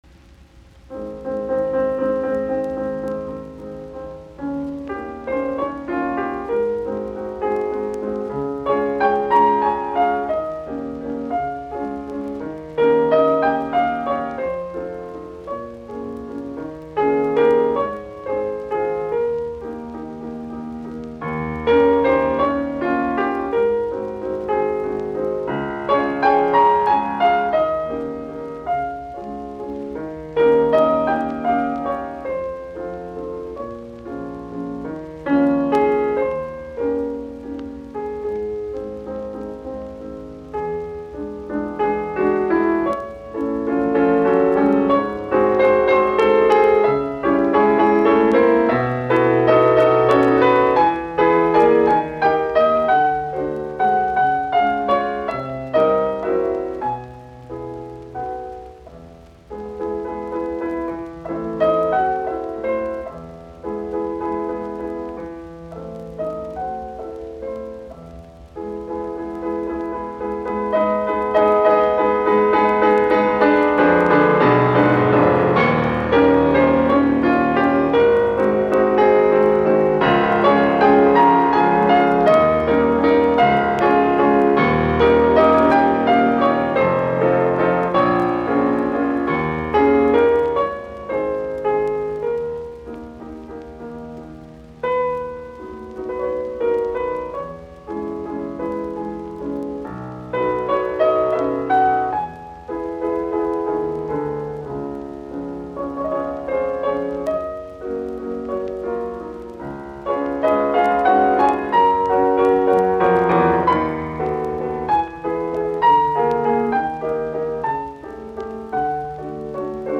in A-flat major, Allegretto